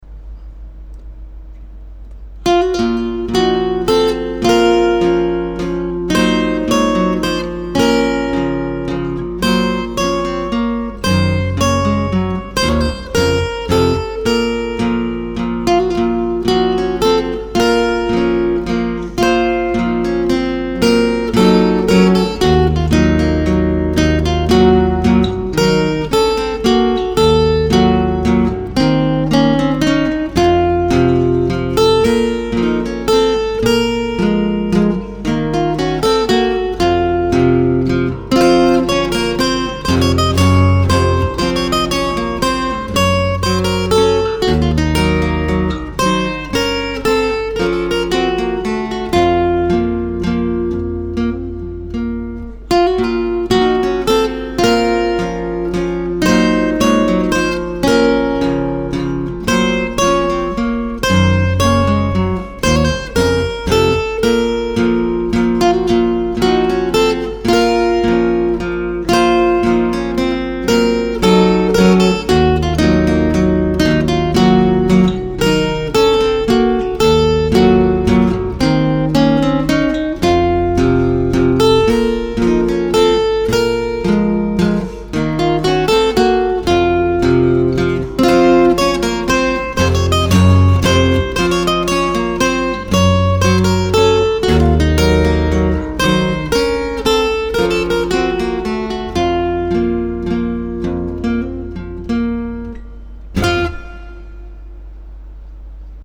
I am surprised there is no more noise from cars and buses, that was one of the noisiest places I ever lived!.